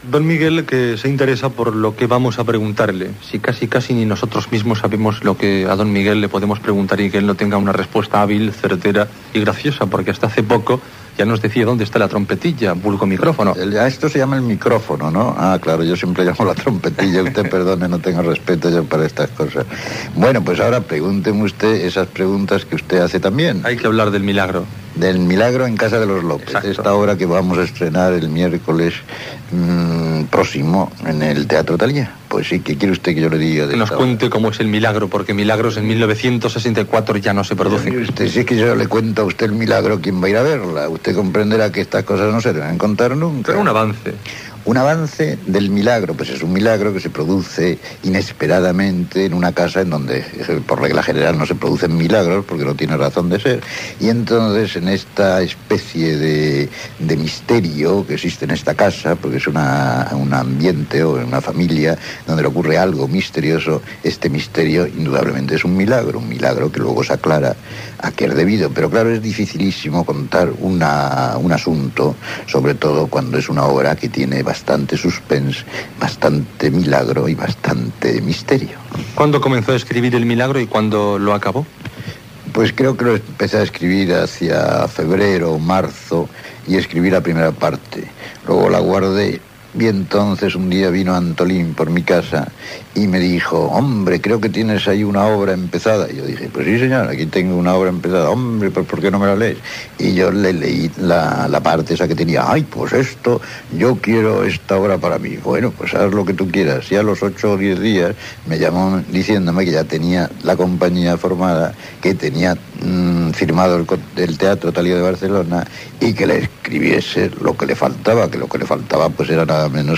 Entrevista a l'escriptor Miguel Mihura per l'estrena de la seva obra "Milagro en casa de los López". Promoció del programa "Meridiano".